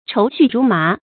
愁绪如麻 chóu xù rú má 成语解释 愁绪：忧愁的心绪。